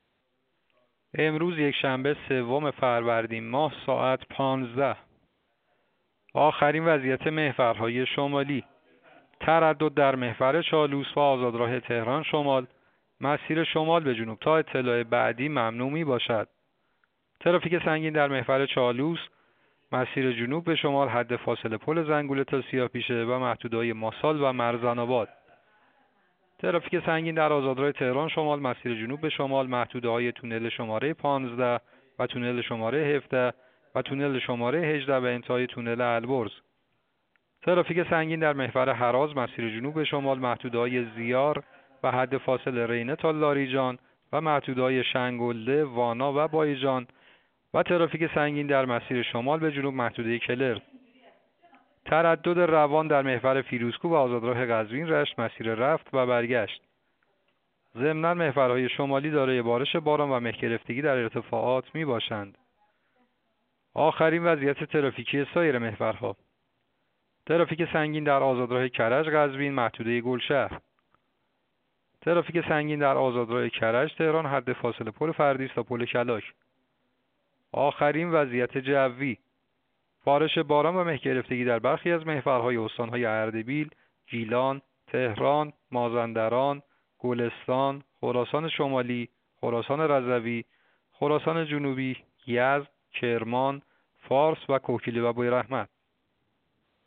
گزارش رادیو اینترنتی از آخرین وضعیت ترافیکی جاده‌ها ساعت ۱۵ سوم فروردین؛